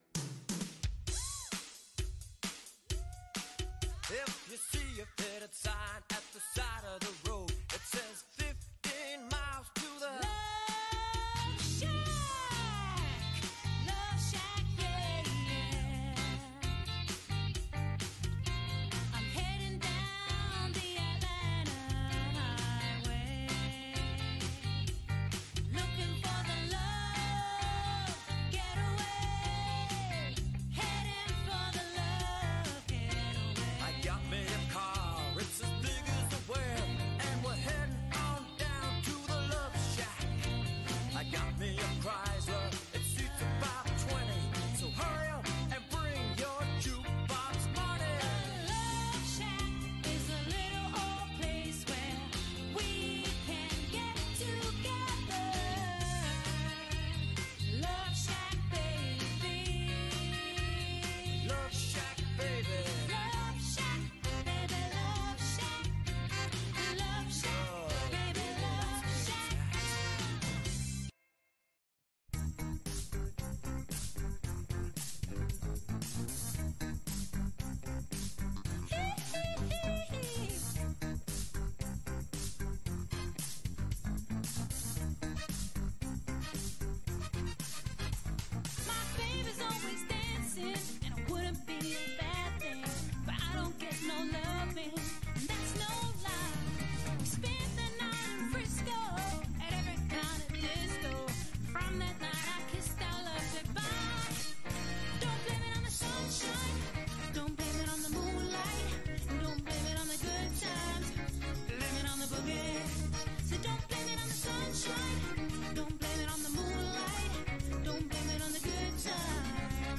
Dance Band Tracks